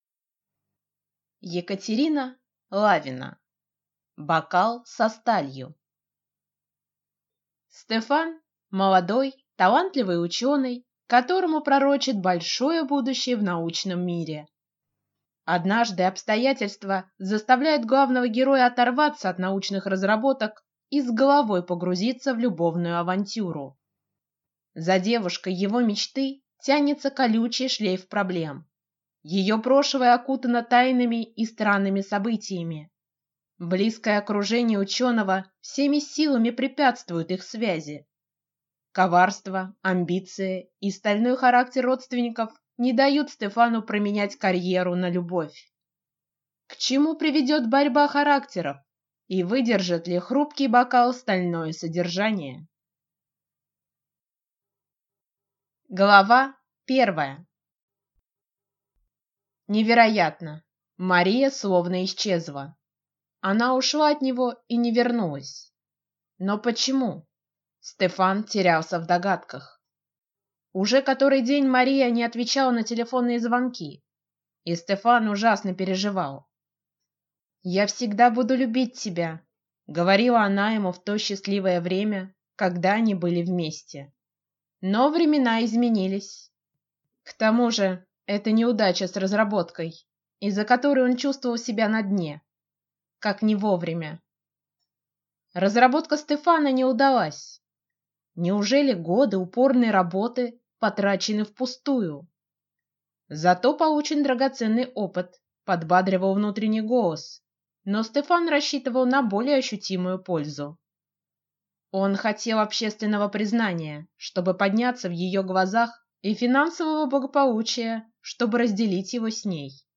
Аудиокнига Бокал со сталью | Библиотека аудиокниг